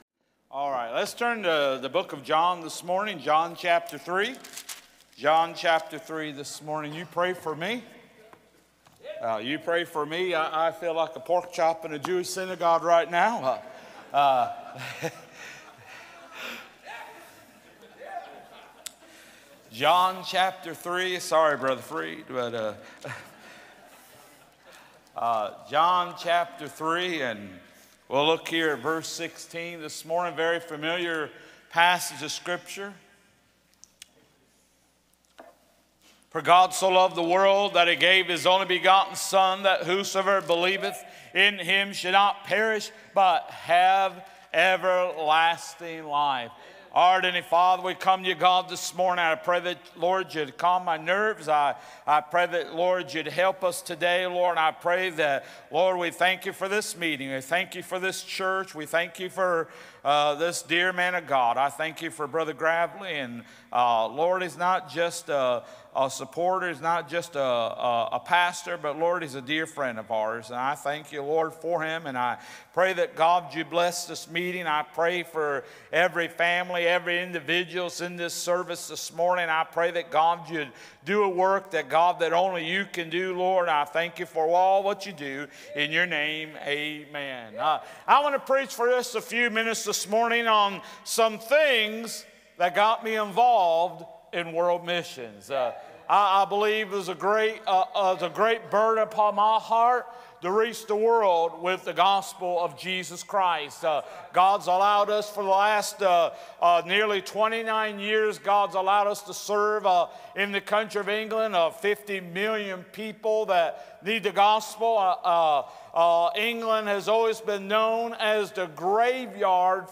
A message from the series "2025 Spring Jubilee."